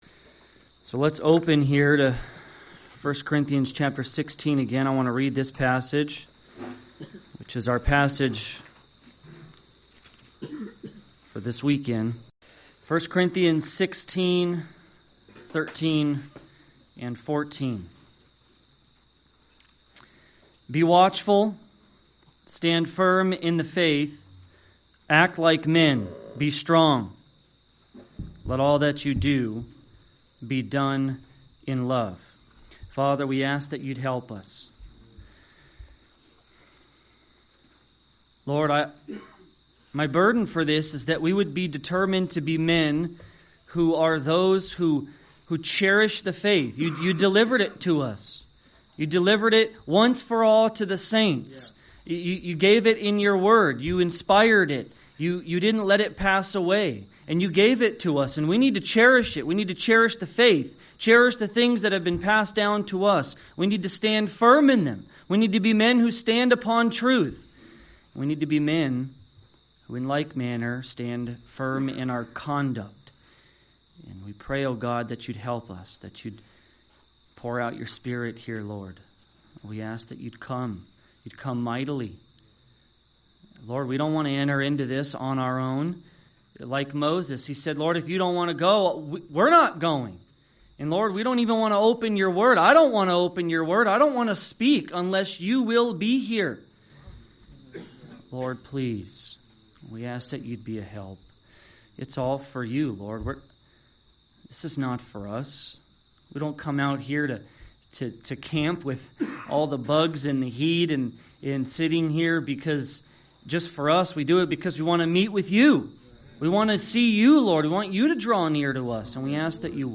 2021 Men's Retreat | Believer, if you are standing firm in the faith and believing solid doctrine, it will affect your conduct.
Category: Full Sermons